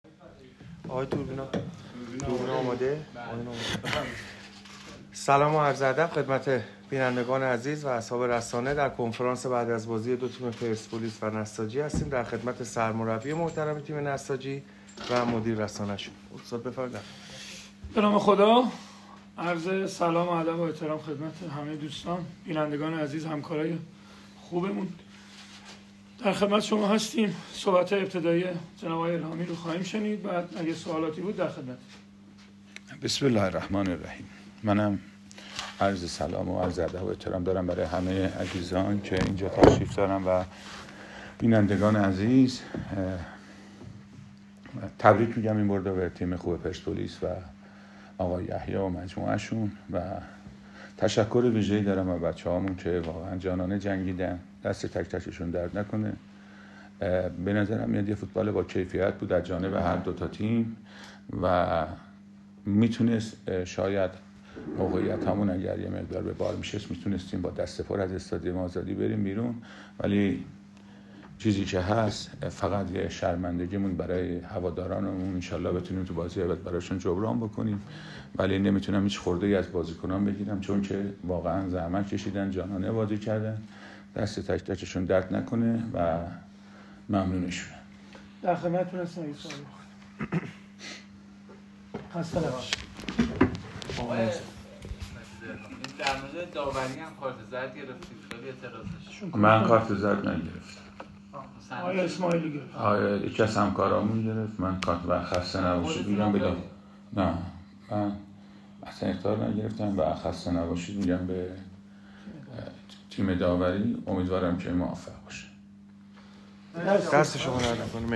کنفرانس خبری گل محمدی و الهامی
کنفرانس مطبوعاتی سرمربی تیم‌های پرسپولیس و نساجی قائمشهر پس از دیدار دو تیم در چارچوب هفته دوم رقابت‌های لیگ برتر برگزار شد.
ساکت الهامی- سرمربی نساجی قائمشهر